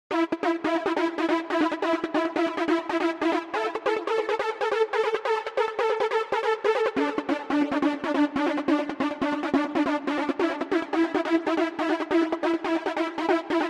门控合成器
描述：闸门式恍惚合成器
Tag: 140 bpm Trance Loops Synth Loops 2.31 MB wav Key : Unknown